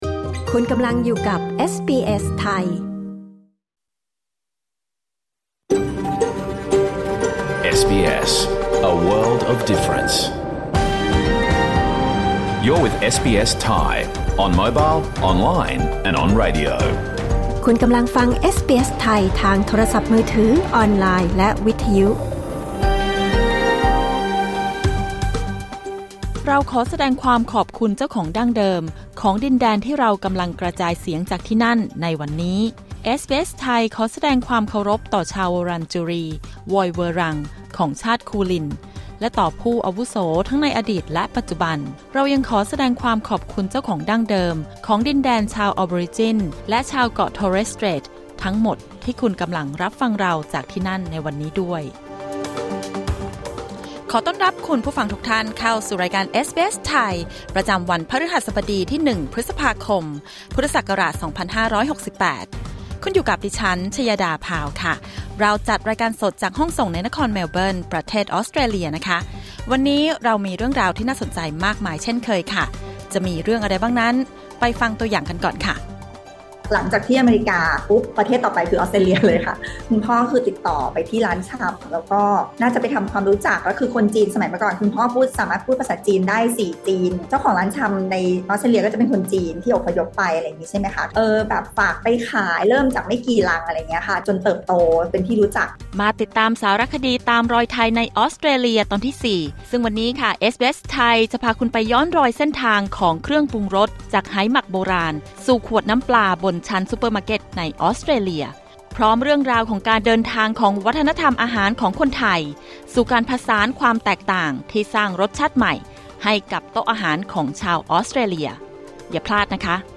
รายการสด 1 พฤษภาคม 2568